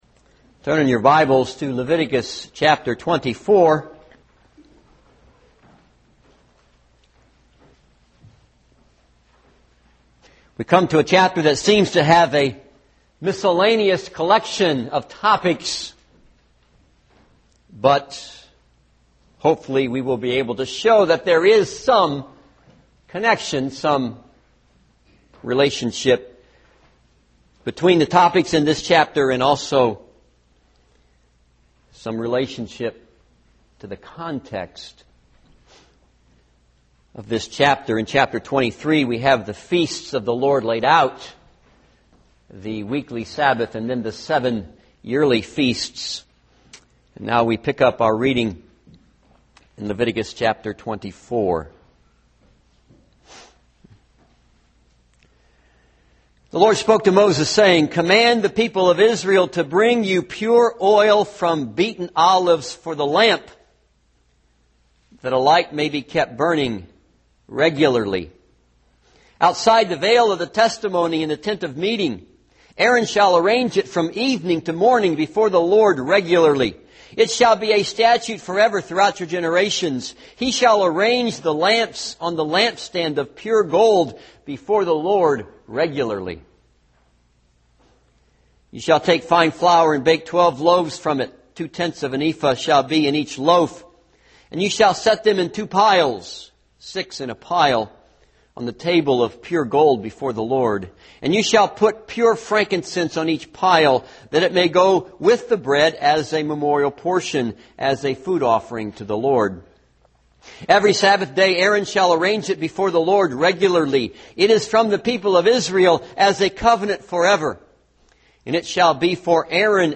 This is a sermon on Leviticus 24.